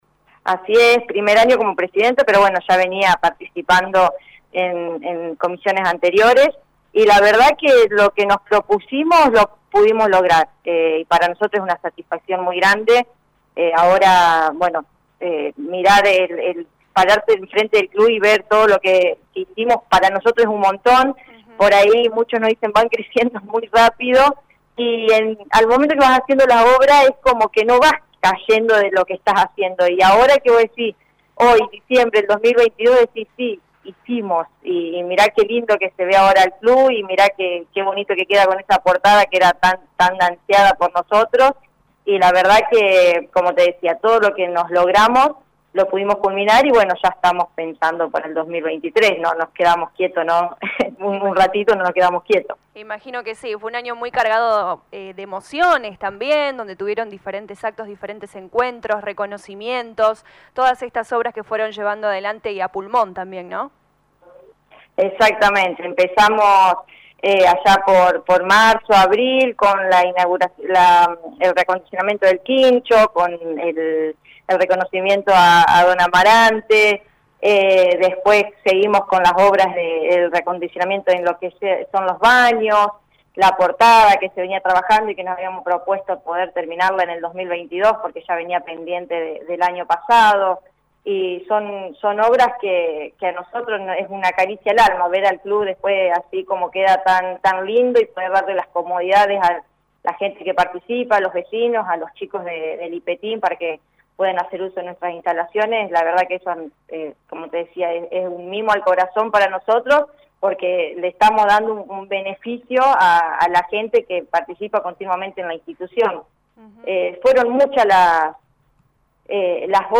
Lo anunció en diálogo con LA RADIO 102.9 FM